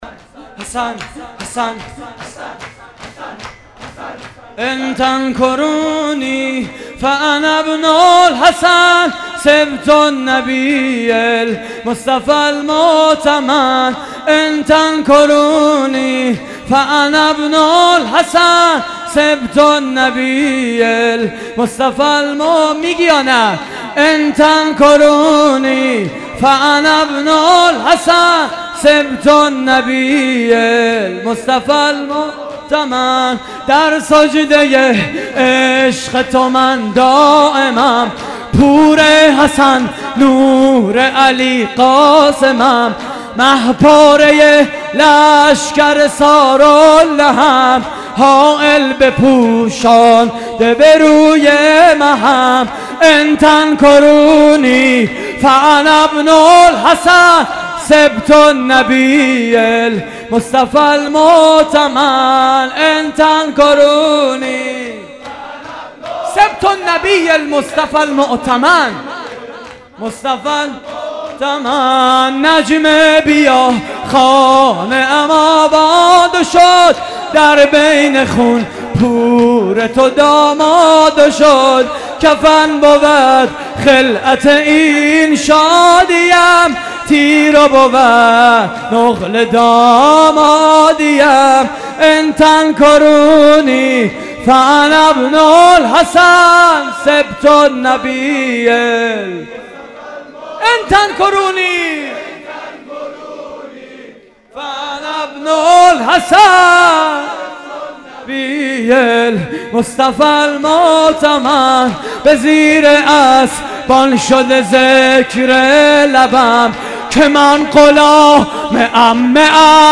شور: ان تنکرونی فأنابن الحسن
مراسم عزاداری شب ششم ماه محرم